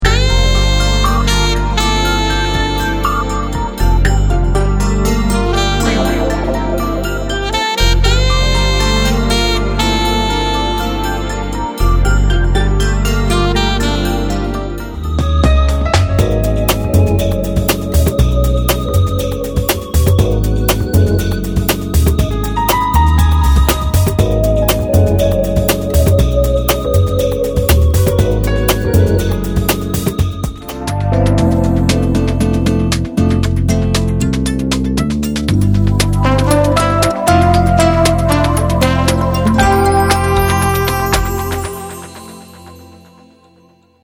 easy-listening
easy-listening.mp3